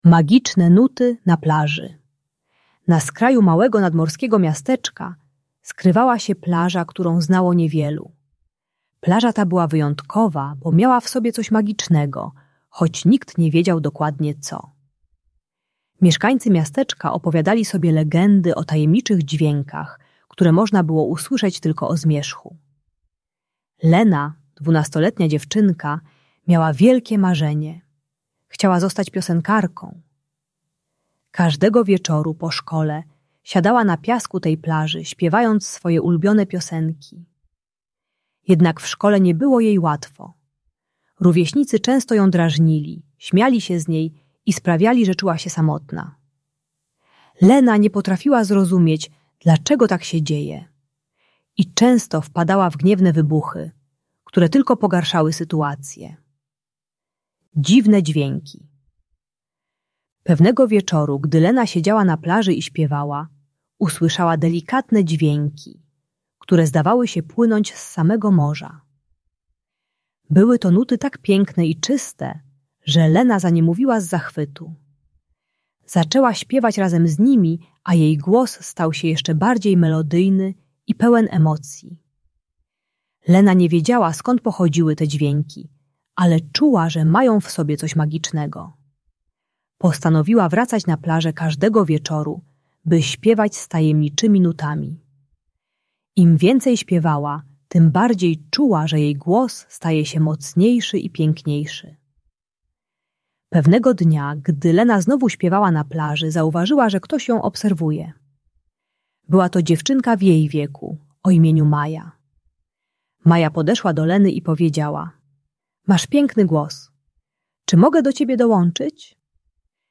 Magiczne Nuty na Plaży - Bunt i wybuchy złości | Audiobajka